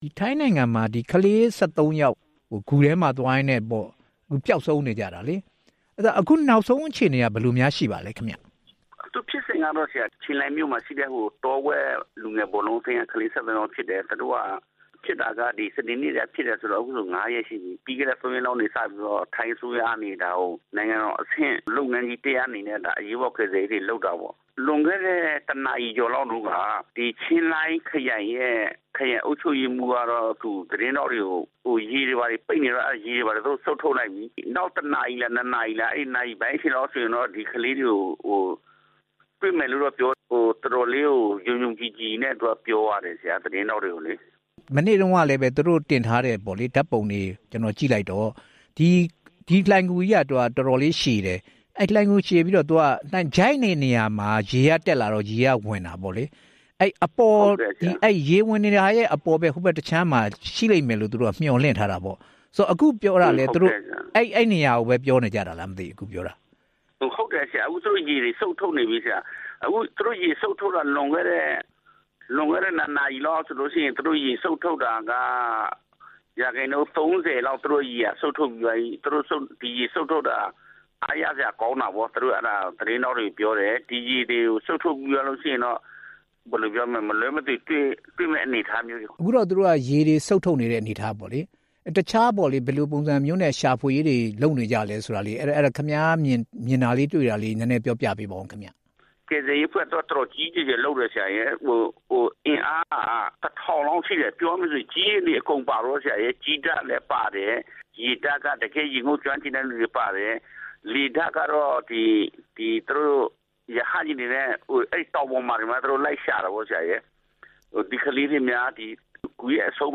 ဆက်သွယ်မေးမြန်းထား ပါတယ်။